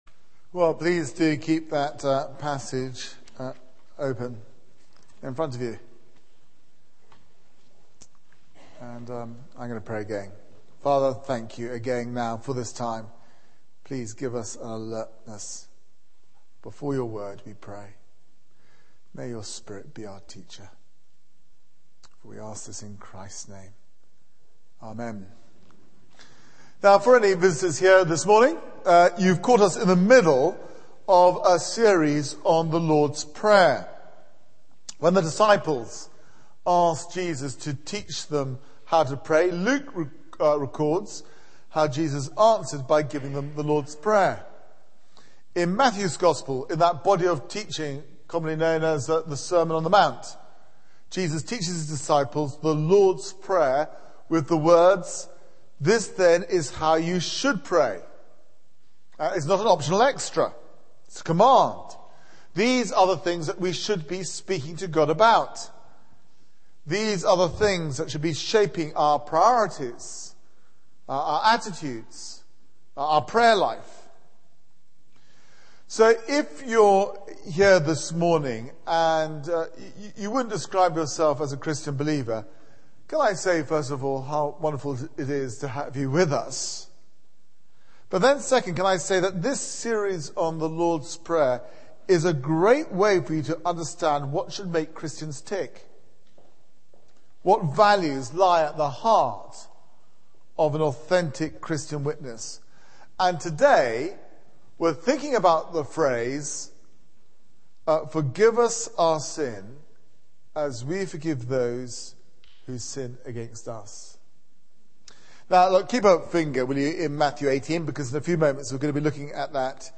Media for 9:15am Service on Sun 24th Oct 2010 09:15 Speaker
Sermon